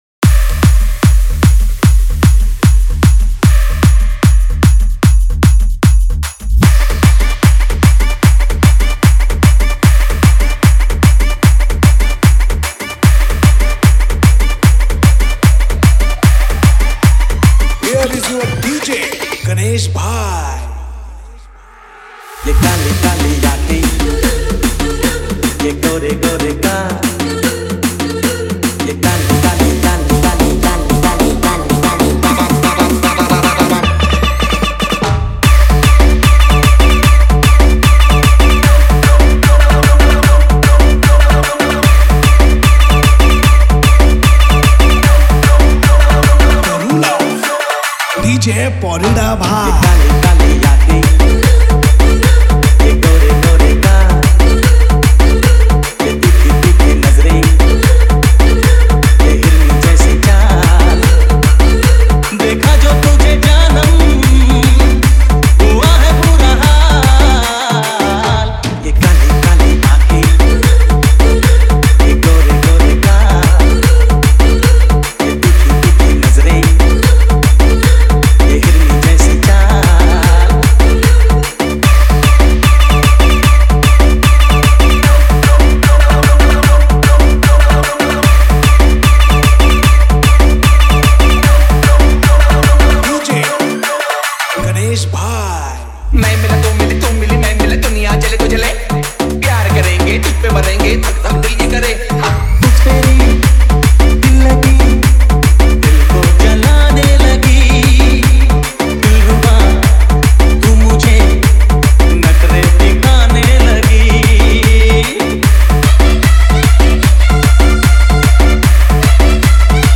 - odia dj song
edm mashup dj music